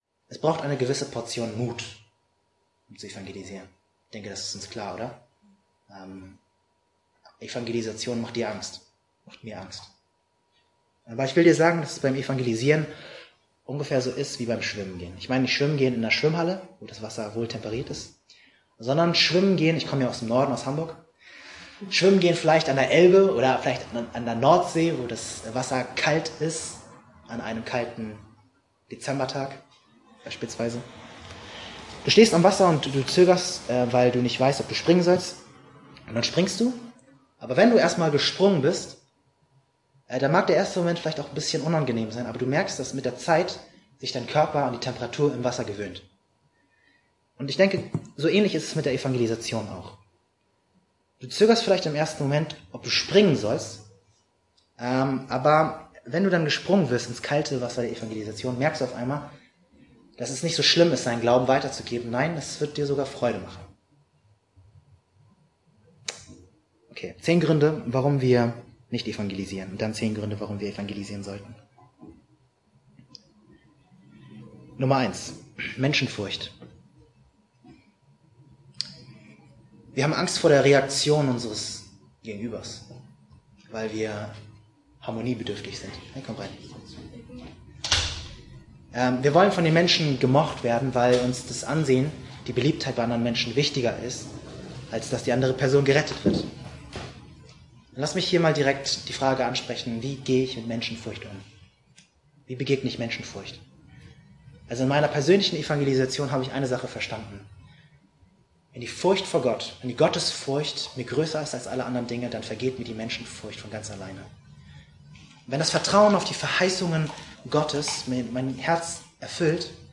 Auch dieses Jahr wurden die Predigten und sogar einige Seminare der Josia-Konferenz aufgenommen.